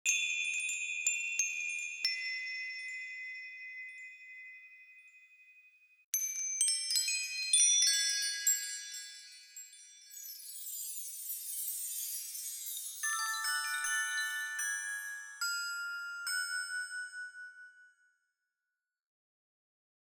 De akoestische kerstdecoraties van Relaxound zijn een hit. Deze Jingle Bells Green sparkles kersthanger is van delicaat glinsterend groen en zijn zachte feestelijke klanken creëren een feest voor de zintuigen.
Relaxound Jingle Bells sieren de kerstboom en hun kalmerende klanken en feestelijke melodieën worden geactiveerd door een bewegingssensor.
• Geluid: 15 - 20 feestelijke kerstdeuntjes